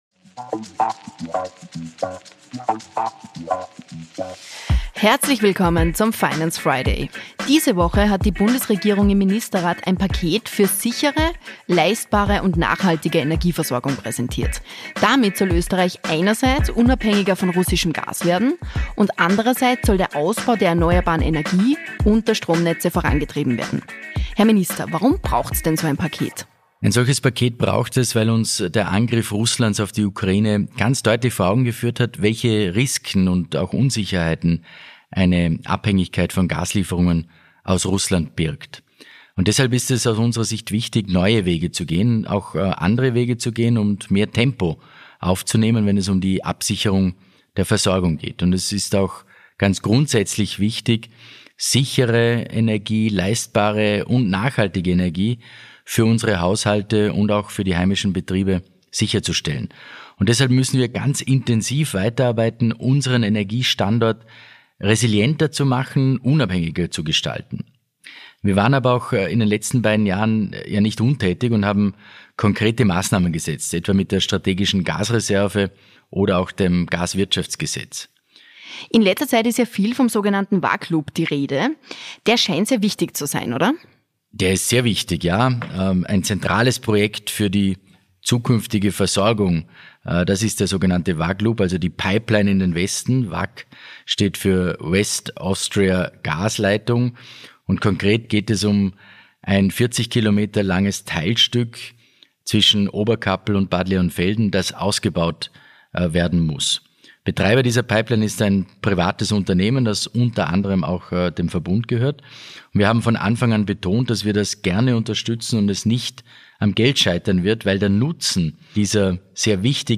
In der aktuellen Folge des „Finance Friday“ spricht Finanzminister